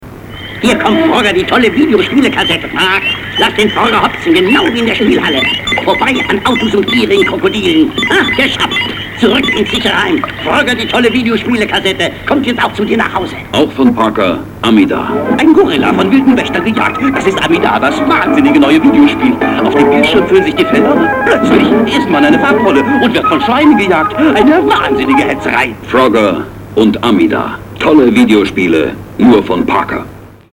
Frogger (Parker Amidar) German Audio Commercial
Great audio commercial in German.
frogger-amidar_commercial.mp3